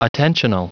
Prononciation du mot attentional en anglais (fichier audio)
Prononciation du mot : attentional